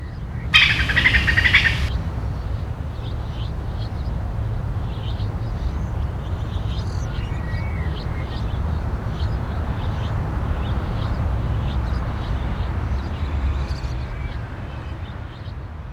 دانلود صدای سنجاب در جنگل برای کودکان از ساعد نیوز با لینک مستقیم و کیفیت بالا
جلوه های صوتی
برچسب: دانلود آهنگ های افکت صوتی انسان و موجودات زنده